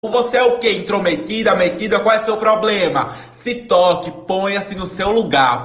Play Robotic Metida - SoundBoardGuy
Play, download and share robotic metida original sound button!!!!